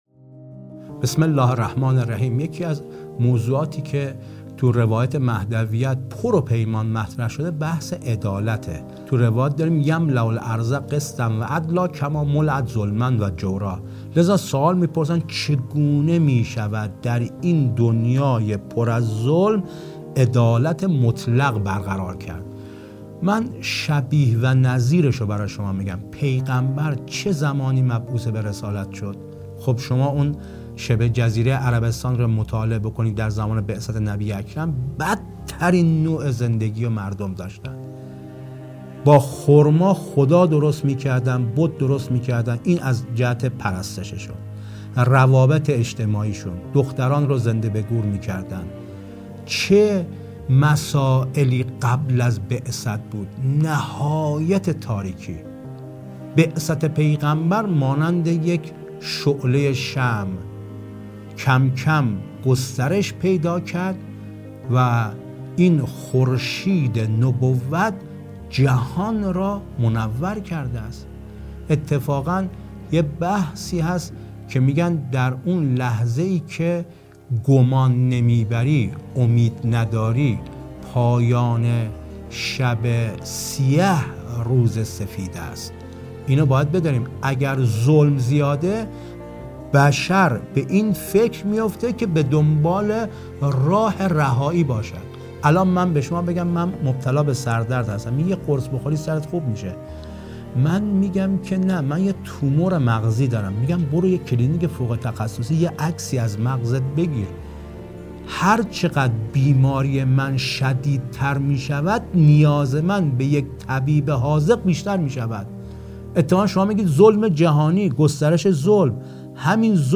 گفتگویی تخصصی